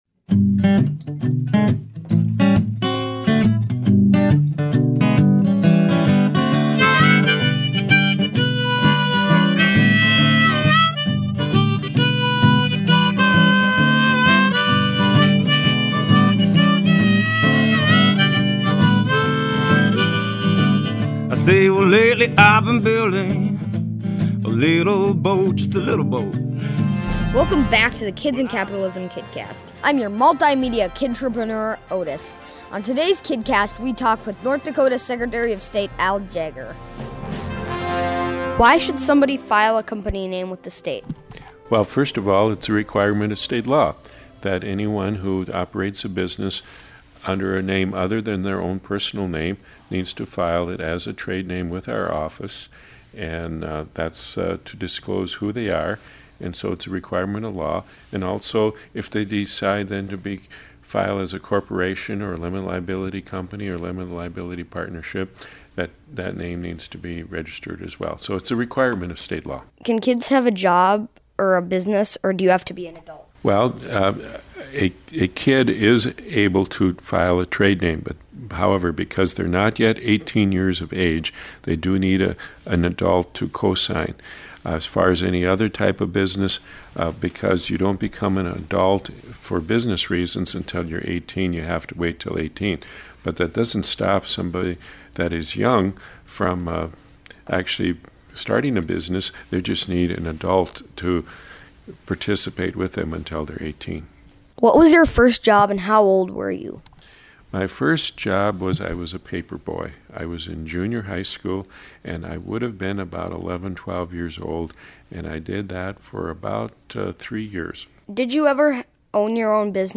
Interviews: Al Jaeger, ND Secretary of State MultiMedia Kidtrepreneur talks to Jaeger and how teenagers and kids can start a company under state law.